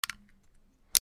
/ G｜音を出すもの / G-01 機器_電話
携帯電話 充電器抜き差し
カチ